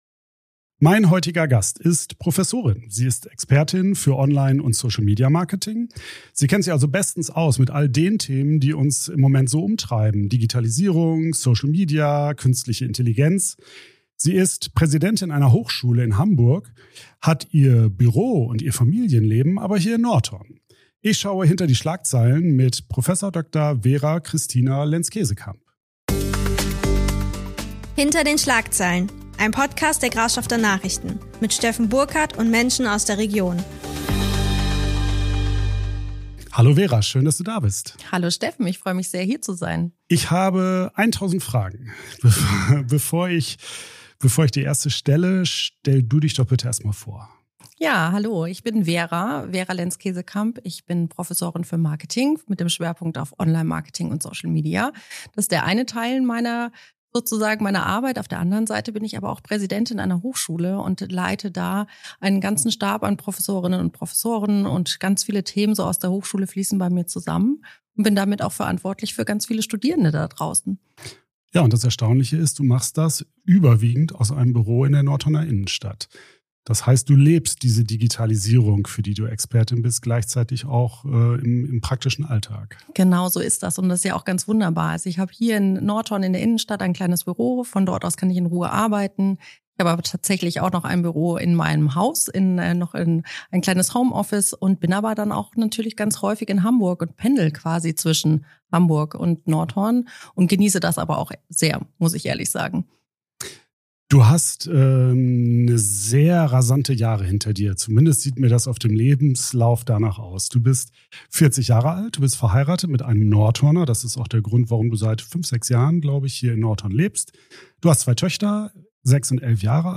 Wir reden über Karriere und Tempo, über Vereinbarkeit von Familie und Beruf, über Frauen in Führungspositionen und über die Frage, ob KI unsere Kreativität bedroht oder neue Chancen eröffnet. Ein Gespräch über Angst und Chancen, Verantwortung und Medienkompetenz – und darüber, wie wir gesünder, bewusster und selbstbestimmter mit der digitalen Welt umgehen können.